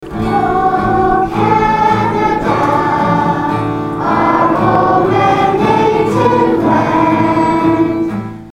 Nelson’s new mayor and council were sworn in Monday night, beginning a four-year term in office.
The proceedings began with a performance from the St. Joseph School choir.